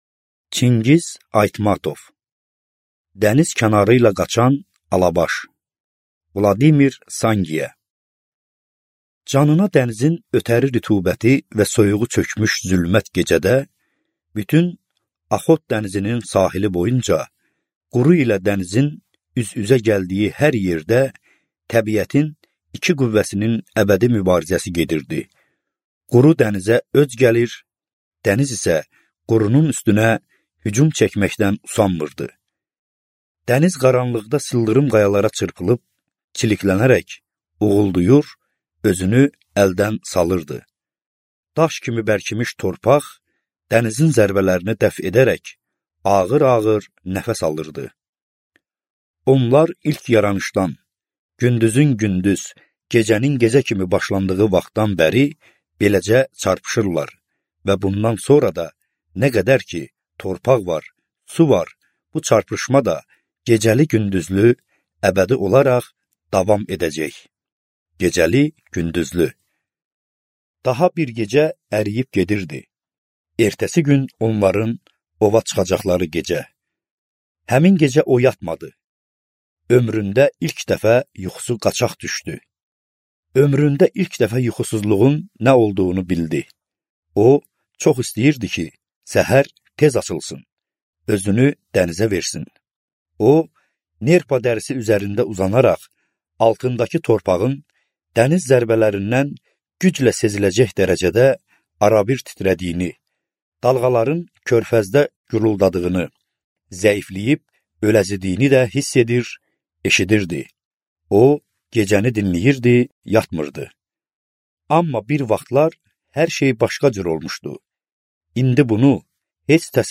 Аудиокнига Dəniz kənarı ilə qaçan alabaş | Библиотека аудиокниг